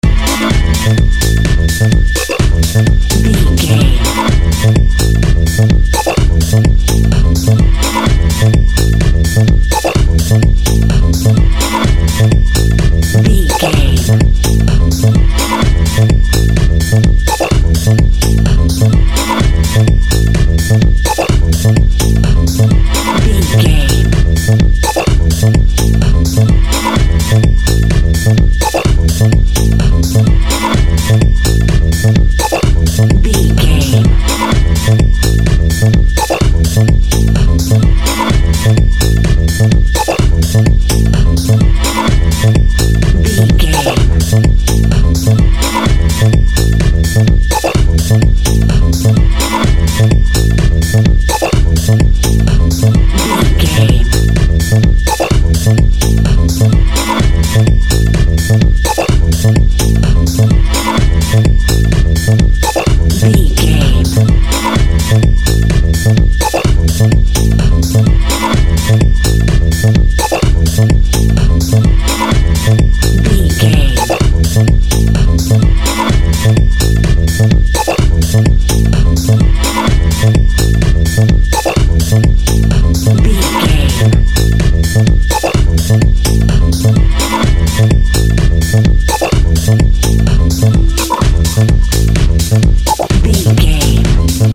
Fast paced
Aeolian/Minor
futuristic
energetic
driving
dark
intense
bass guitar
synthesiser
drum machine
Drum and bass
break beat
electronic
sub bass
synth lead